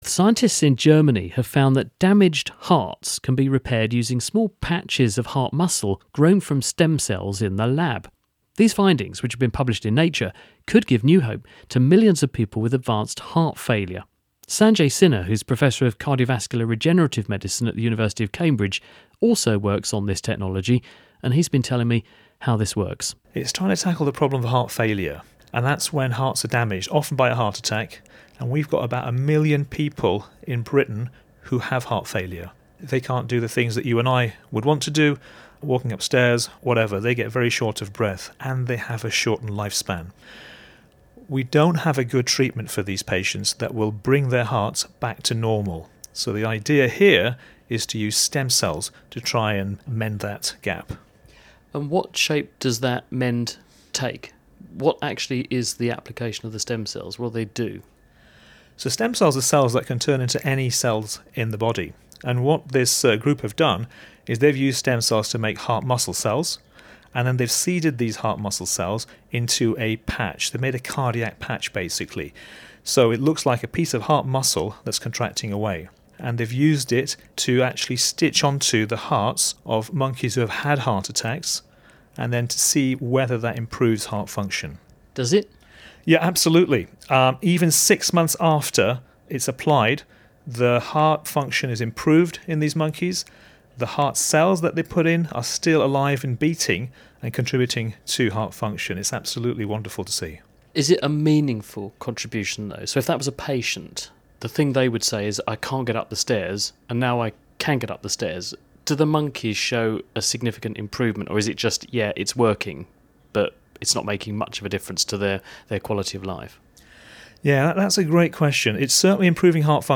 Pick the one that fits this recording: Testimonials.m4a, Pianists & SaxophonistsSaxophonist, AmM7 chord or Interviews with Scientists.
Interviews with Scientists